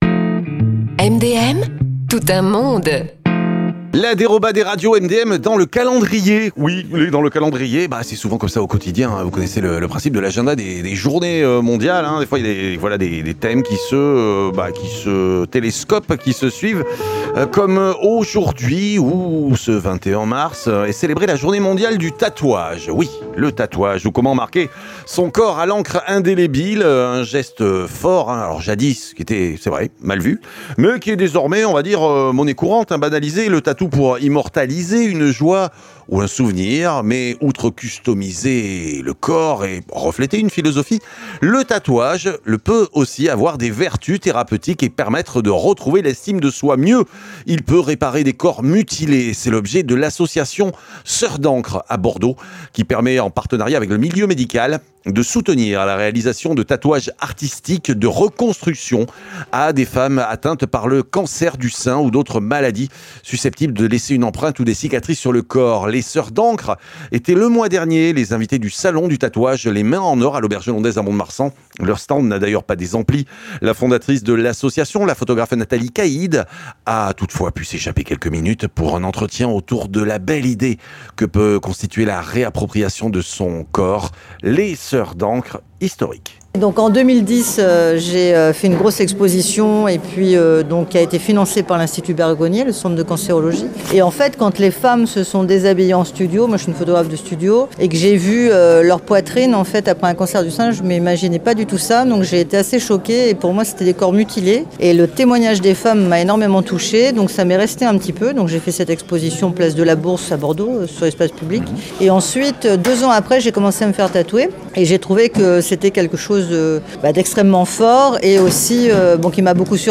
(Entretien réalisé le mois dernier lors de la Convention les Mains d’Or à Mont de Marsan)